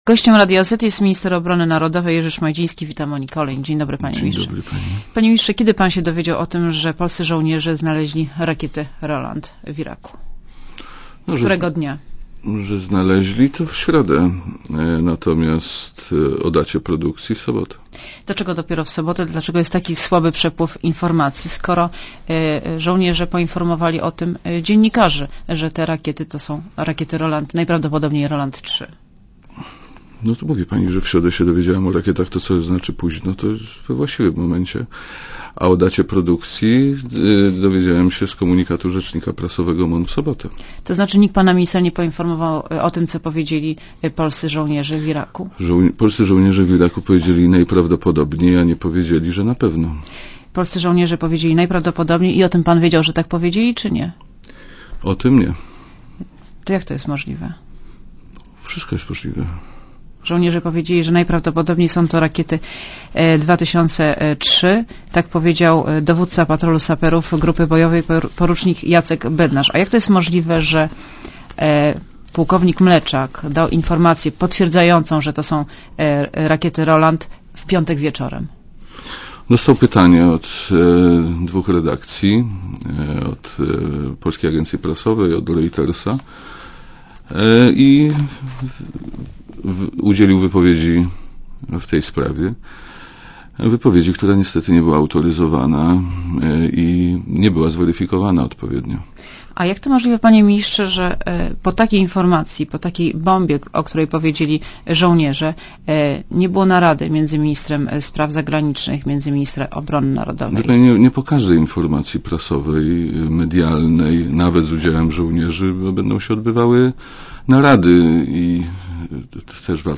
Monika Olejnik rozmawia z ministrem Jerzym Szmajdzińskim (2,96 MB)